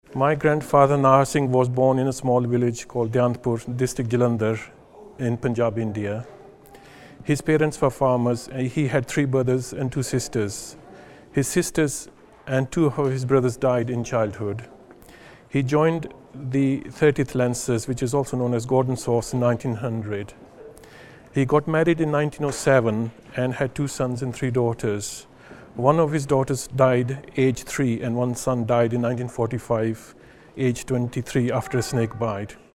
Location: Brunei Gallery, London